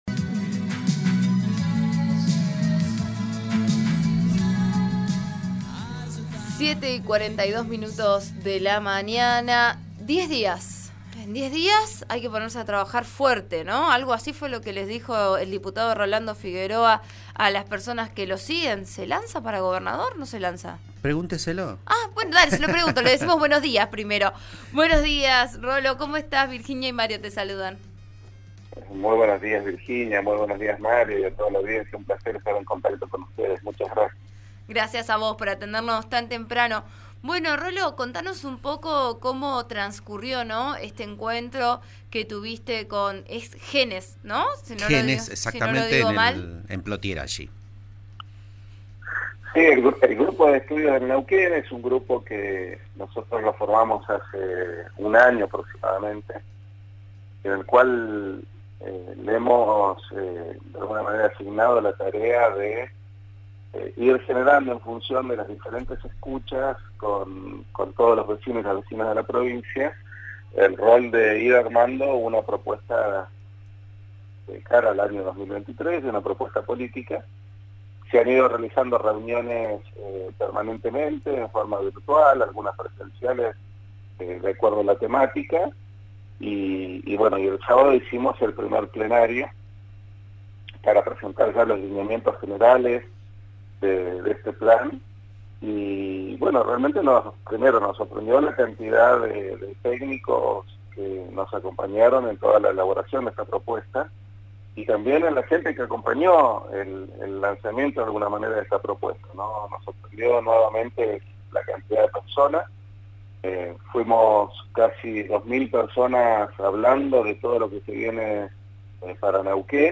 El diputado nacional por el Movimiento Popular Neuquino, Rolando Figueroa, dialogó con Vos A Diario RN Radio acerca de la estafa, en la que aún se intenta determinar el destino de los 123 millones de pesos que fueron sustraídos del Banco Provincia de Neuquén Este monto se habría sido sustraído en el lapso del 2020 y julio de 2022.